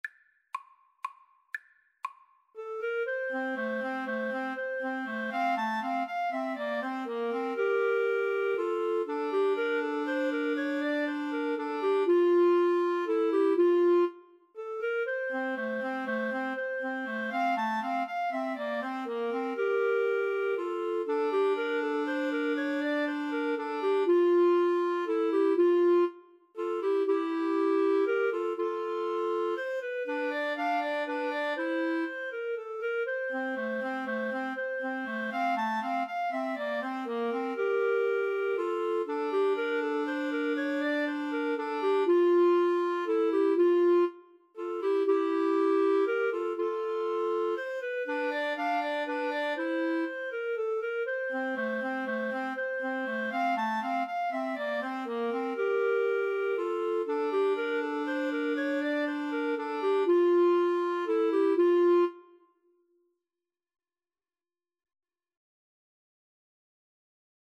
3/4 (View more 3/4 Music)
Clarinet Trio  (View more Easy Clarinet Trio Music)
Classical (View more Classical Clarinet Trio Music)